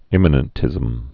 (ĭmə-nən-tĭzəm)